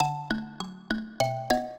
mbira
minuet8-11.wav